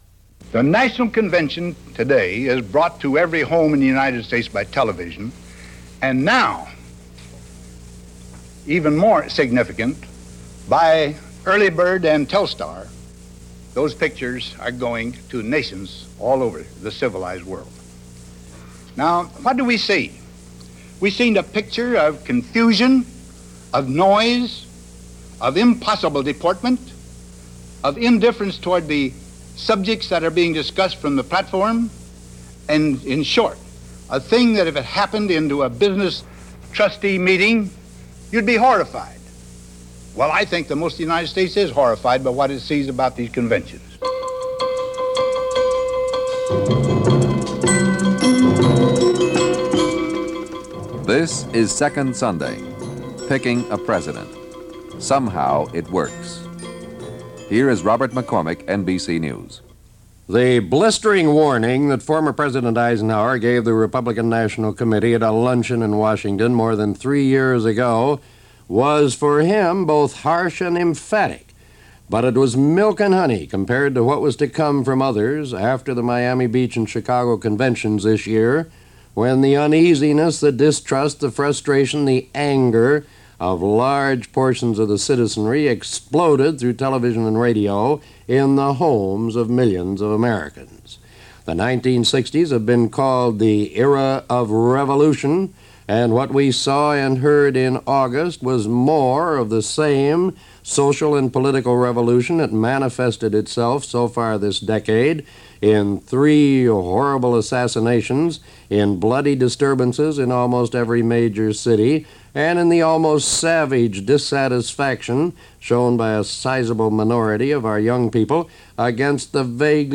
From October 17, 1968 NBC Radio ran as part of it’s monthly documentary series Second Sunday, “Picking A President”.